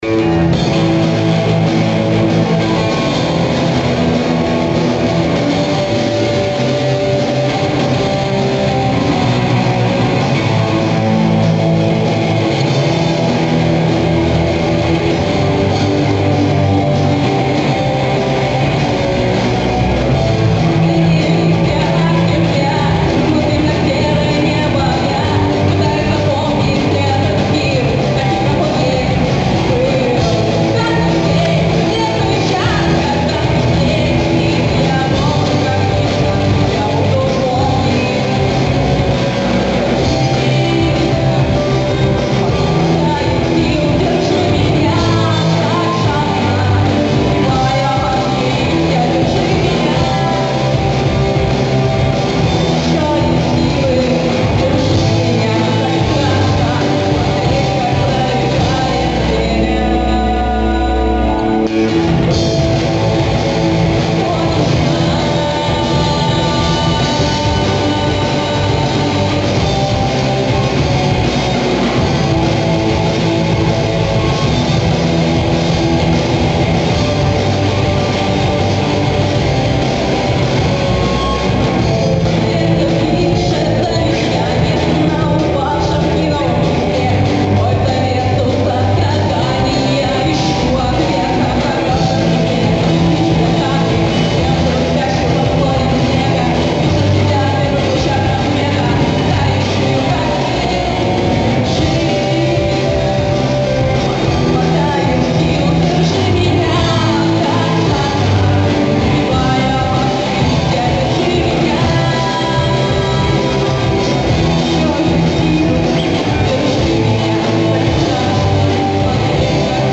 Акустическая версия композиции